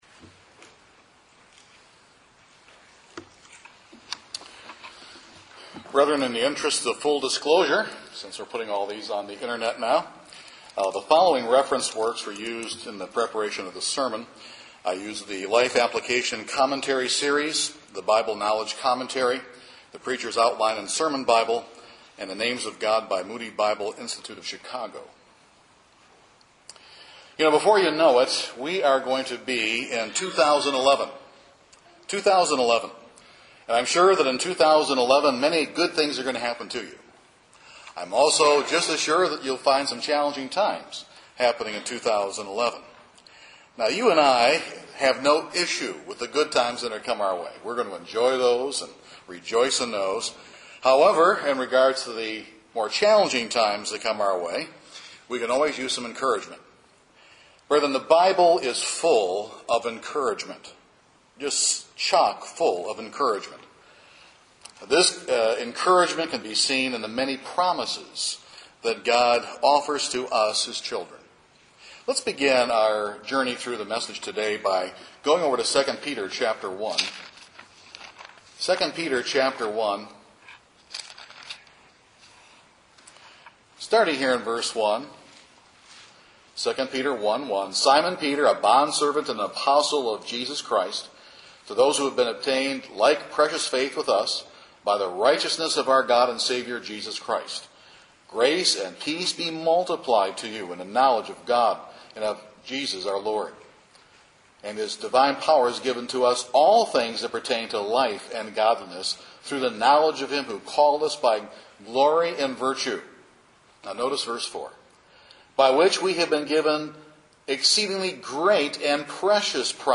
However, the Bible is full of God’s encouraging promises. This sermon helps us focus on the masterpiece not the mess!